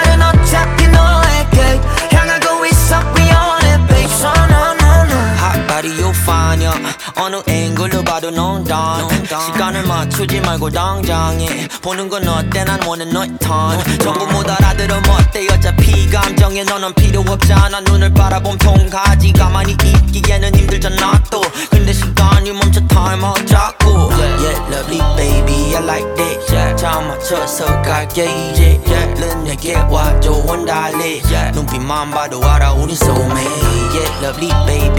Hip-Hop Rap Pop K-Pop
Жанр: Хип-Хоп / Рэп / Поп музыка